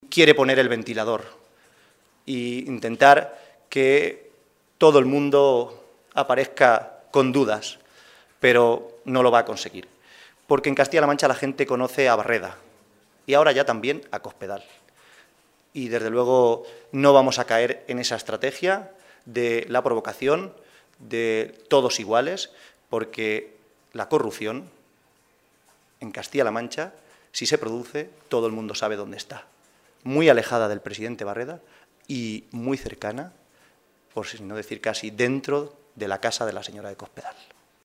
El secretario de Organización del PSOE de C-LM, José Manuel Caballero, ha asegurado hoy, durante la celebración del Comité Regional de este partido, que “los socialistas de C-LM nos hemos conjurado para no dar ni un paso atrás en el mantenimiento y mejora de lo que más importa a los ciudadanos de nuestra Región, que es una sanidad y una educación pública de calidad, y una política social dirigida a los que más lo necesitan”.